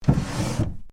На этой странице собраны уникальные звуки комода: скрипы ящиков, стук дерева, движение механизмов.